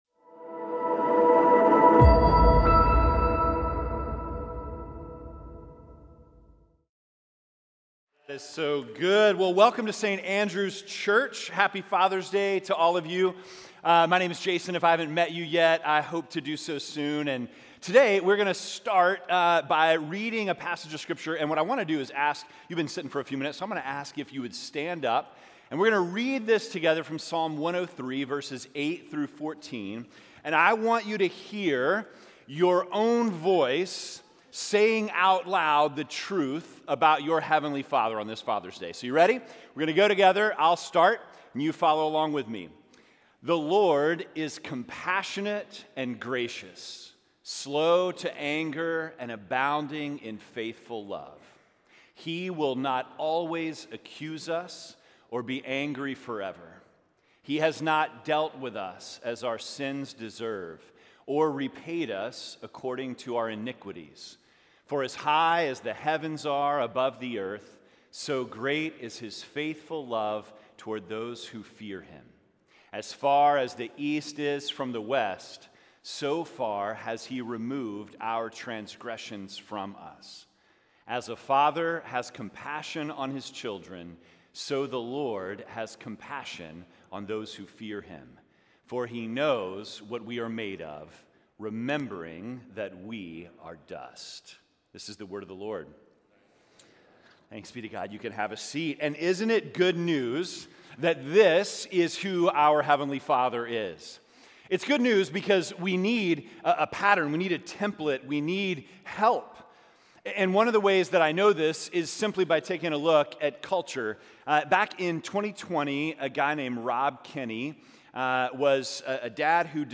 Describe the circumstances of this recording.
A Special Service Celebrating Father’s with SACI Sing Helping in Worship.